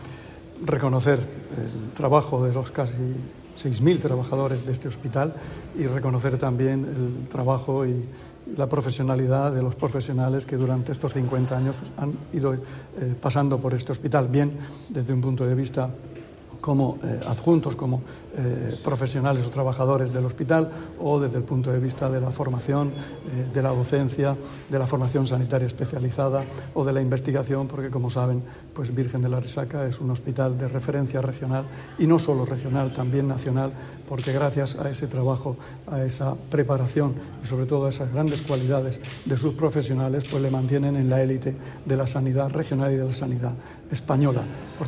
Sonido/ Declaraciones del consejero de Salud, Juan José Pedreño, en la clausura de los actos del 50 aniversario del hospital Virgen de la Arrixaca.